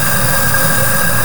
F VOX NOISE.wav